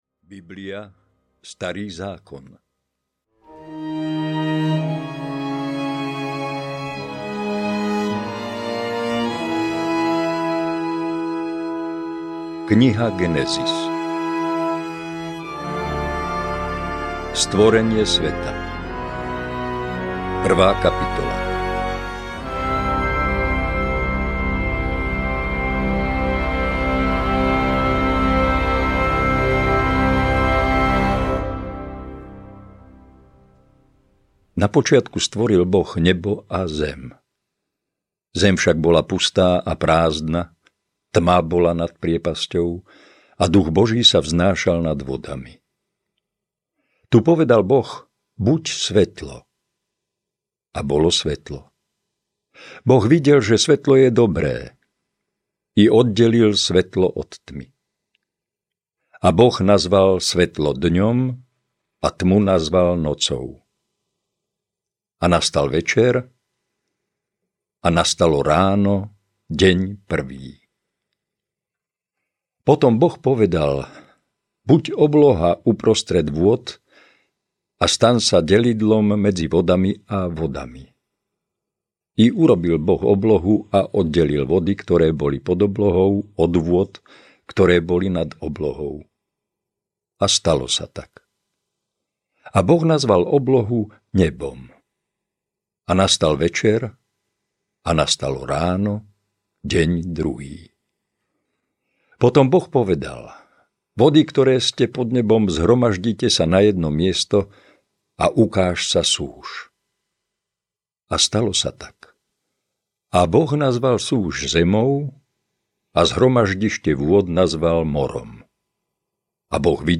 Biblia audiokniha
Hudbu zložil skladateľ Lukáš Borzík.„Keď čítame Sväté písmo, Boh sa vracia poprechádzať do pozemského raja,“ hovorí svätý Ambróz, milánsky biskup a učiteľ Cirkvi. Kniha kníh nie je len posvätným textom kresťanov a Židov, ale aj inšpiráciou mnohých umelcov.